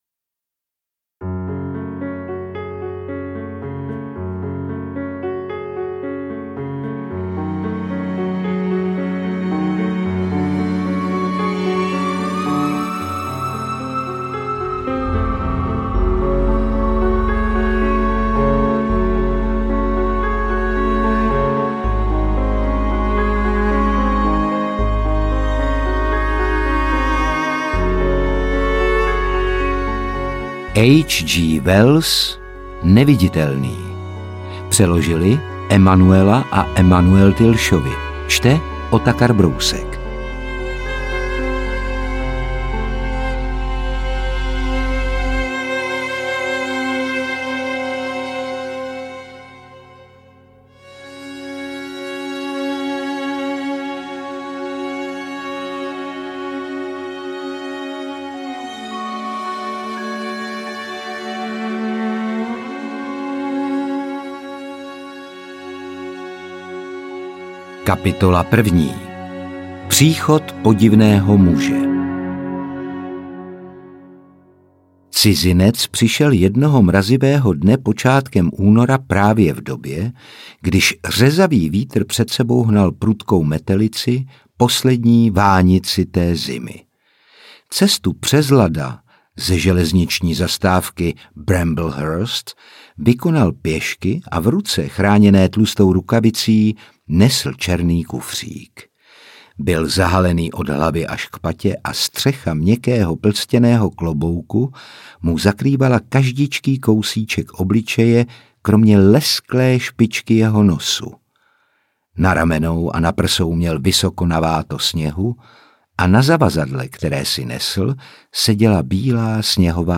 Interpret:  Otakar Brousek ml.
AudioKniha ke stažení, 29 x mp3, délka 7 hod. 4 min., velikost 386,6 MB, česky